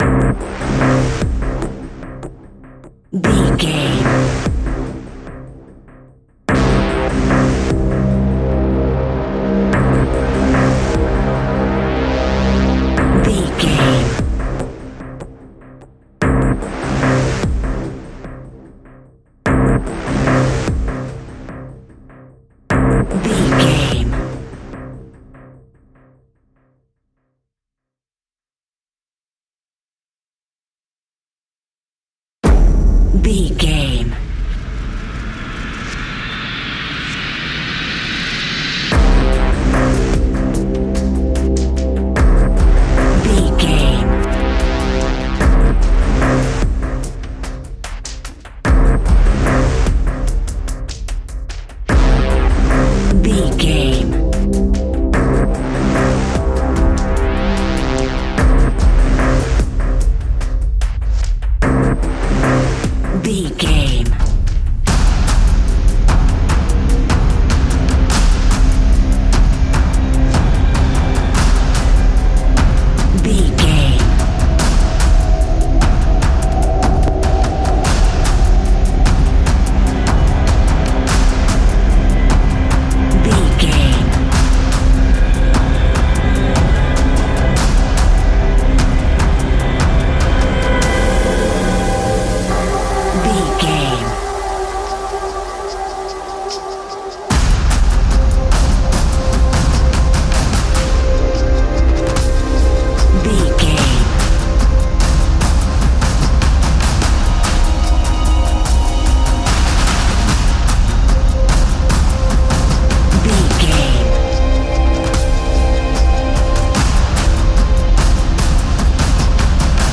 Fast paced
In-crescendo
Ionian/Major
C♯
industrial
dark ambient
EBM
synths
Krautrock